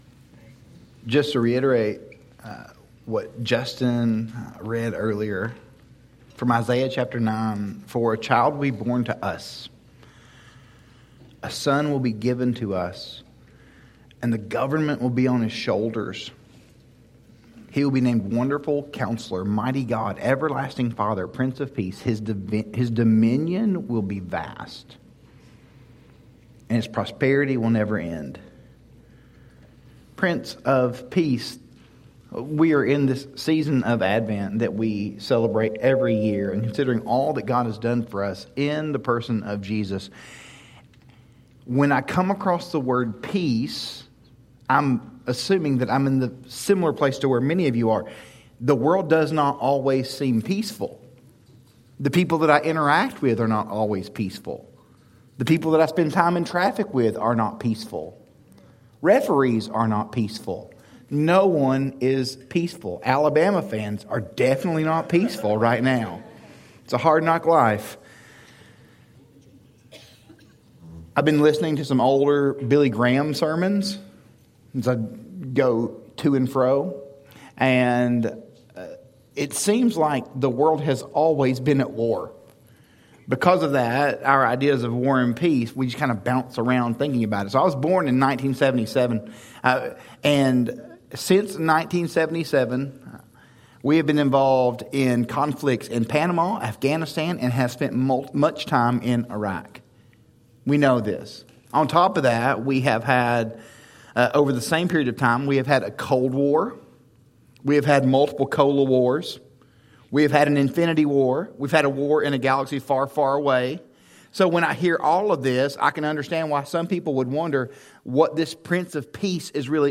Prince of Peace - A Sermon